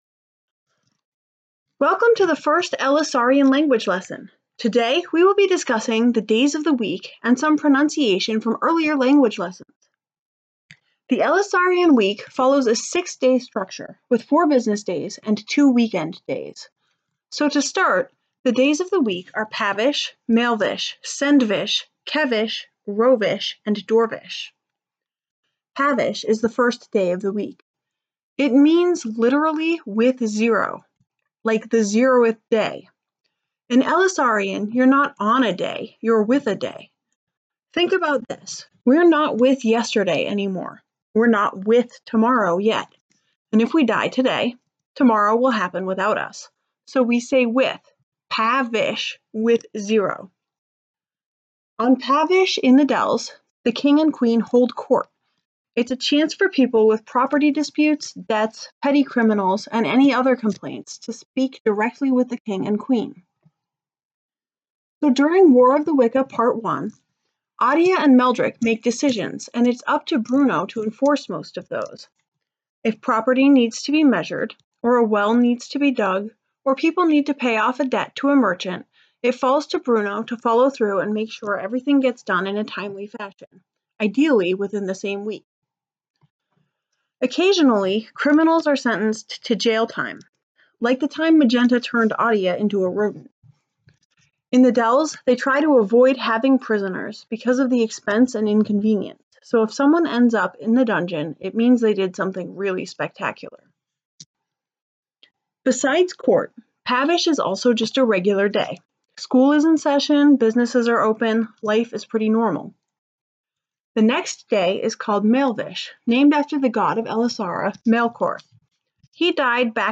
This audio lesson reviews pronunciation for some conversation pieces and introduces the days of the week:
Language-Lesson-Weekdays.m4a